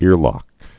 (îrlŏk)